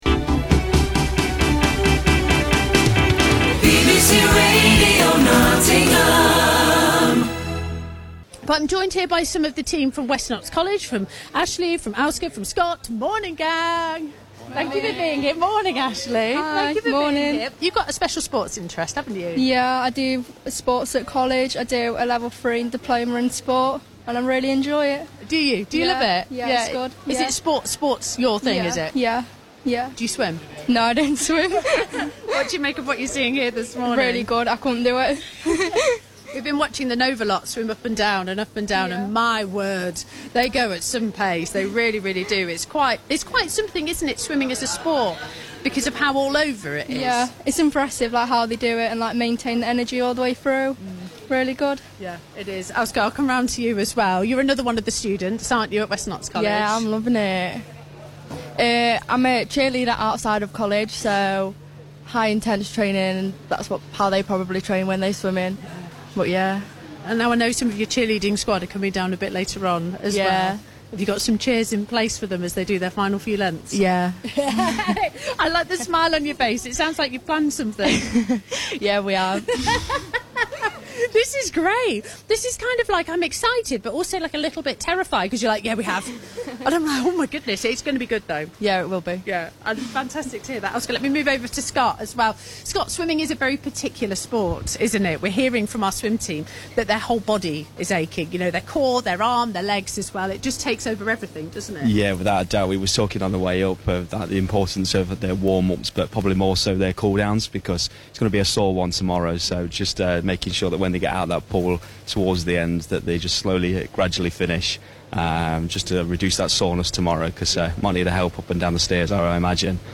BBC_Radio_Nottingham_Swimathon.mp3